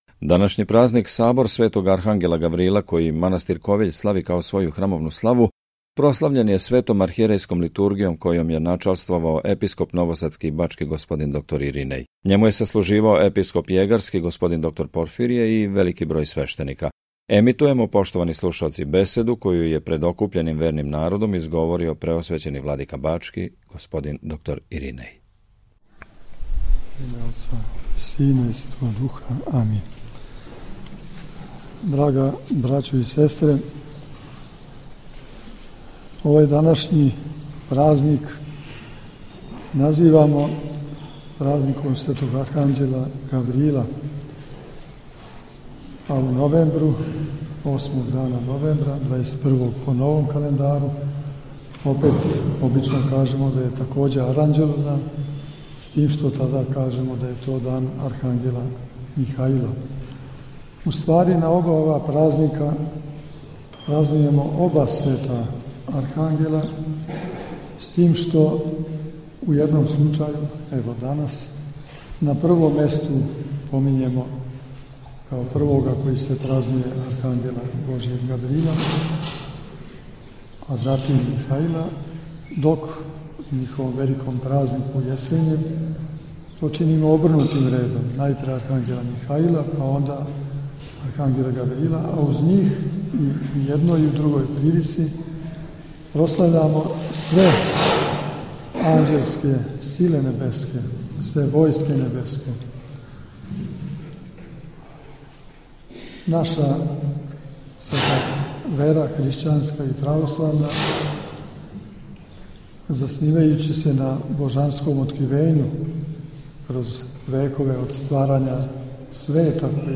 Обитељ Светоархангелског манастира у Ковиљу прославила је престолни празник своје цркве евхаристијским слављем којим је началствовао Његово Преосвештенство Епископ бачки Господин др Иринеј, уз саслужење Епископа јегарског Господина др Порфирија, настојатеља манастира, многобројних свештеникâ и вернога народа.